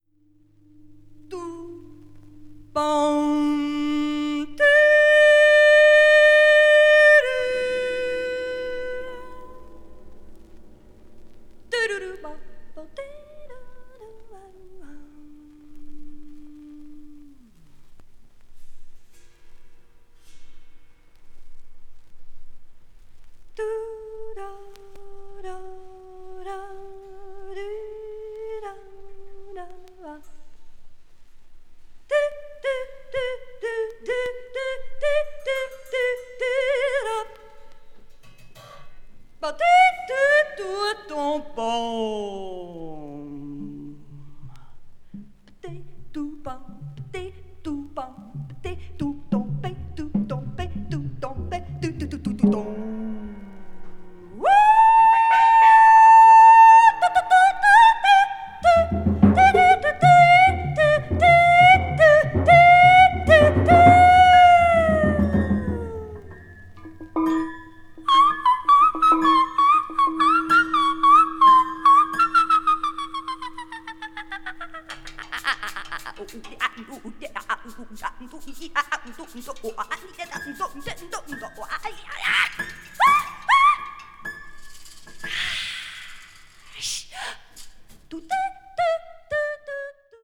media : EX/EX,EX/EX(わずかにチリノイズが入る箇所あり)